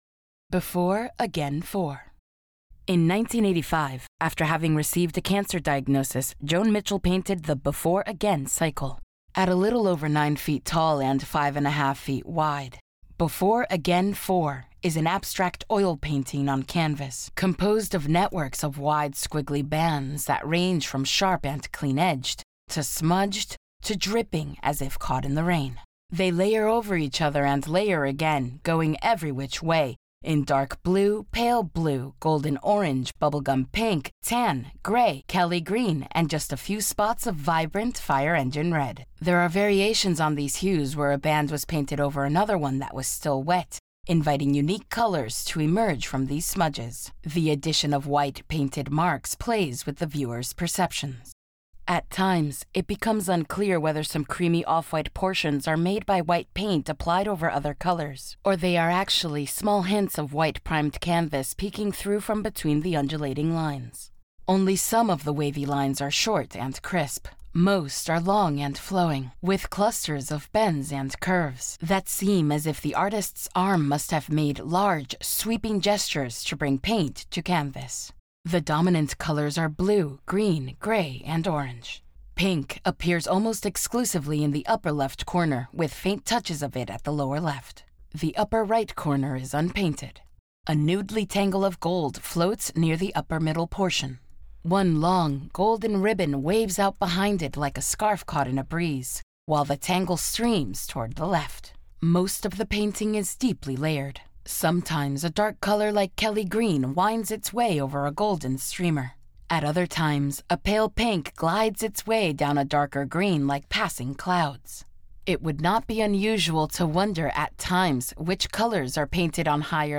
Audio Description (02:18)